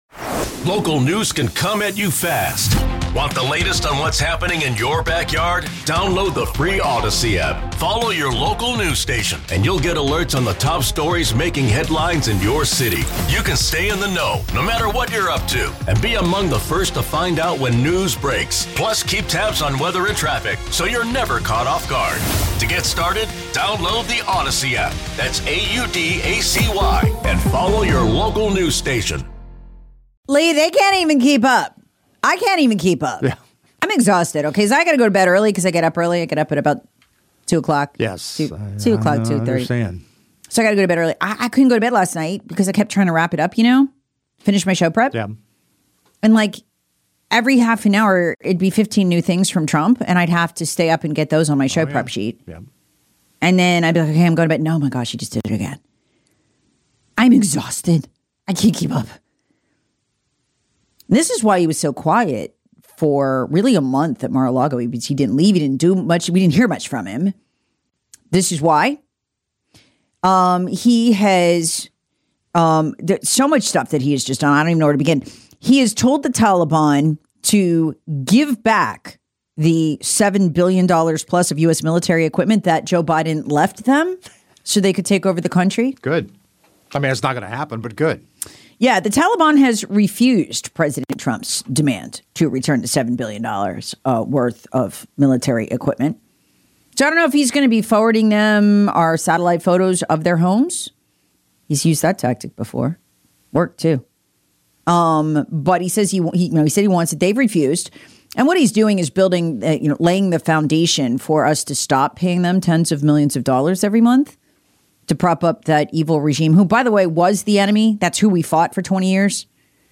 all news/talk morning show